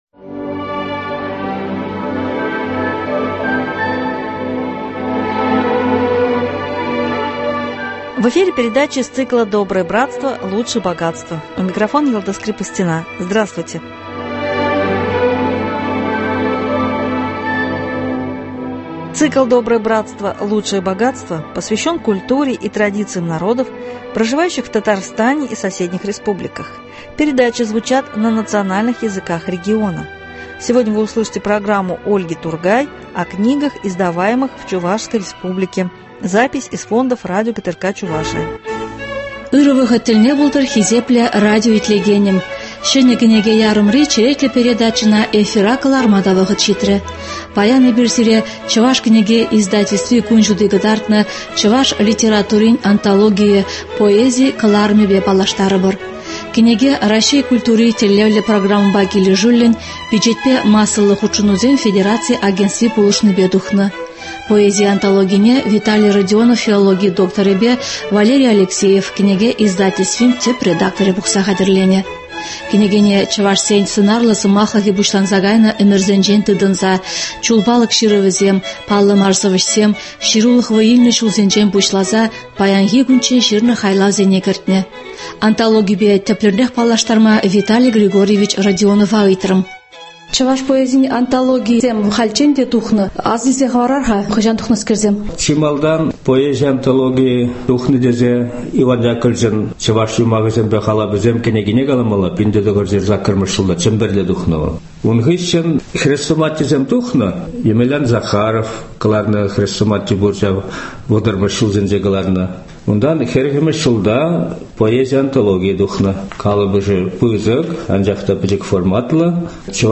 Запись из фондов радио ГТРК Чувашия (на чувашском языке).